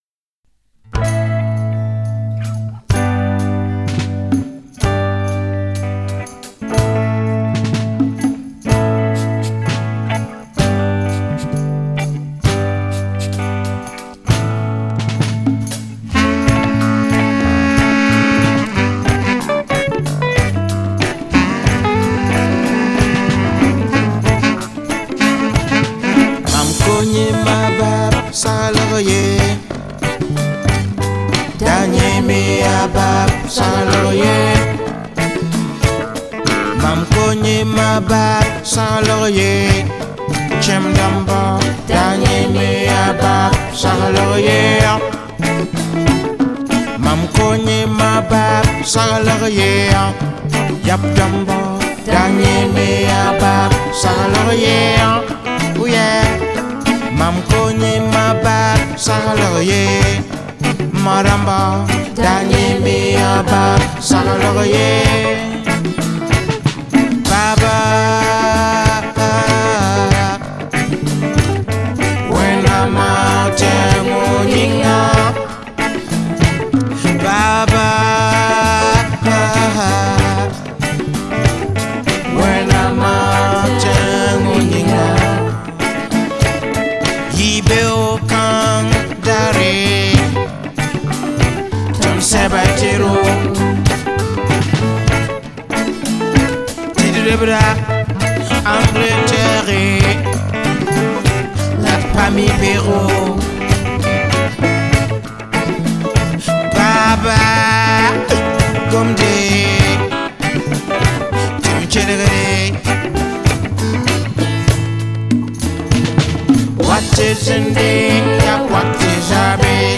Reggae
Keyboard
Sax and Flute
Backing Vocals
Bass